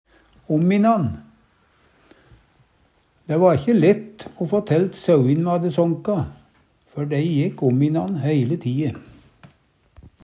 ominan - Numedalsmål (en-US)